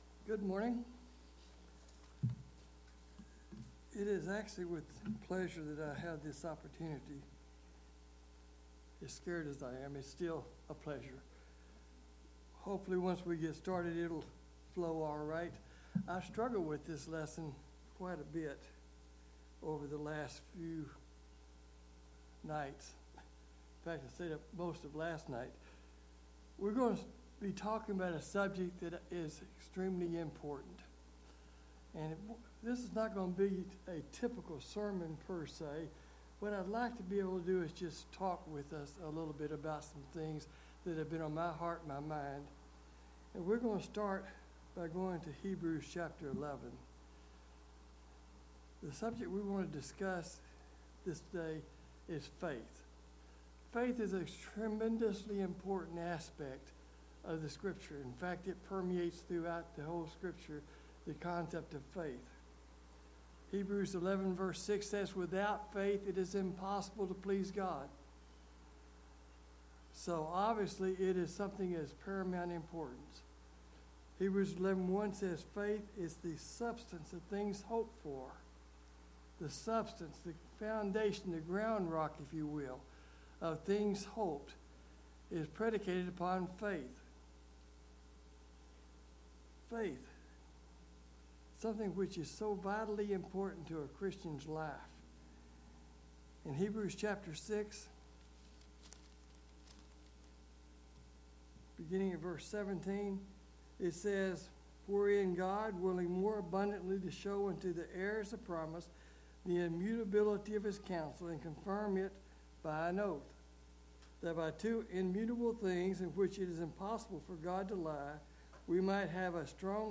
6 Service Type: AM Worship Bible Text